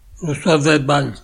Prononciation occitane : Le Saut des Banes 00:00 / 00:00 Sommaire